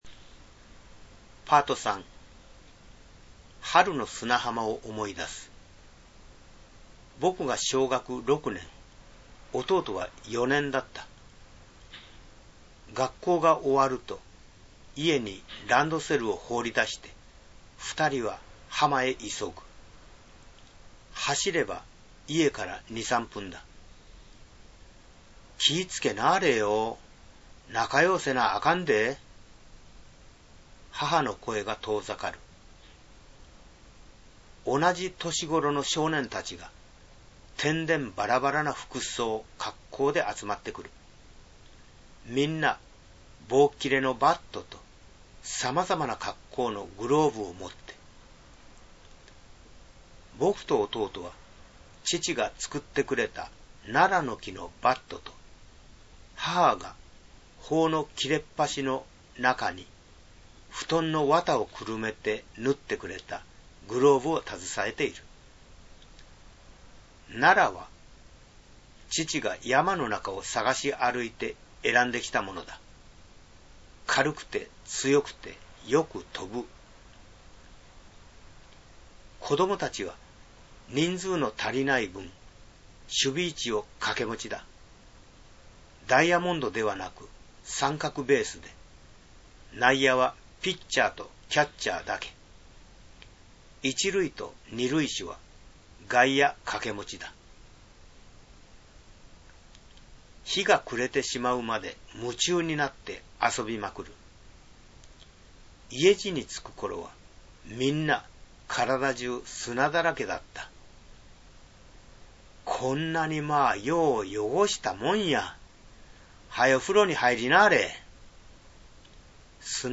Ｐａｒｔ３　春の想い出 Ｐａｒｔ３朗読(6'40")